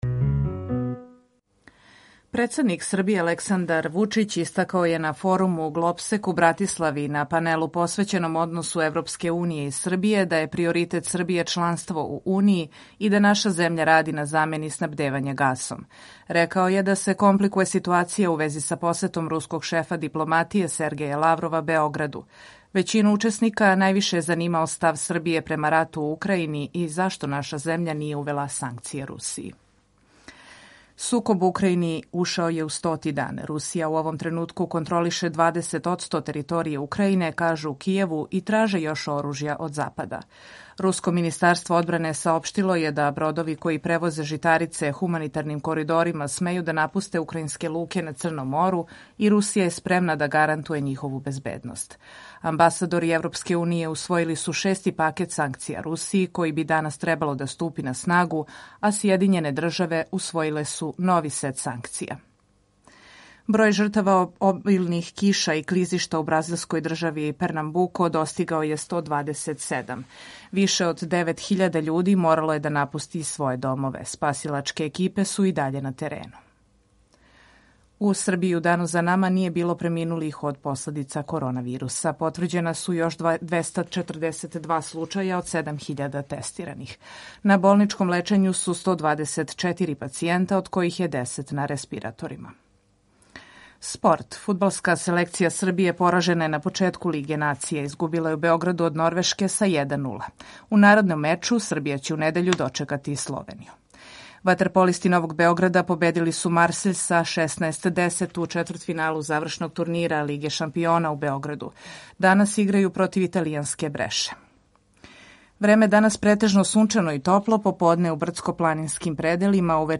Емисију реализујемо заједно са студиом Радија Републике Српске у Бањалуци и са Радио Новим Садом.
Јутарњи програм из три студија
У два сата, ту је и добра музика, другачија у односу на остале радио-станице.